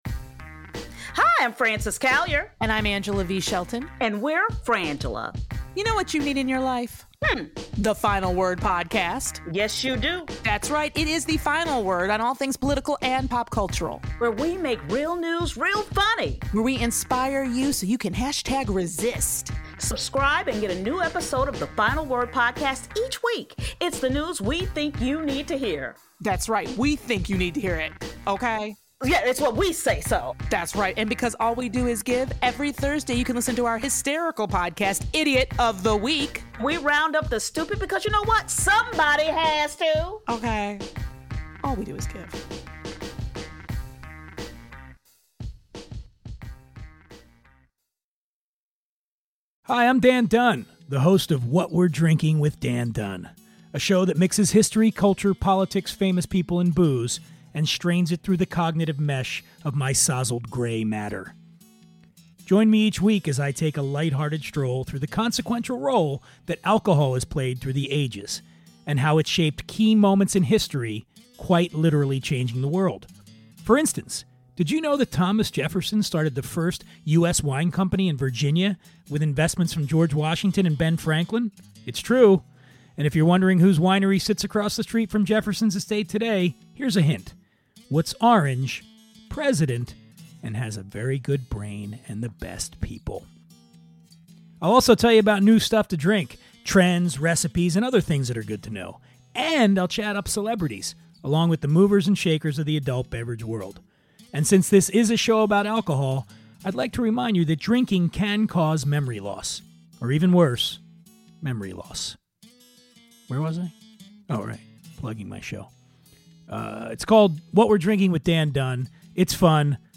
I'm not always politically correct and I'm a huge fan of loose talk and salty language. Sarcasm is guaranteed and political correctness will be at a minimum. This podcast is for anyone who wants to listen to compelling conversations with a variety of guests about current events and controversial topics.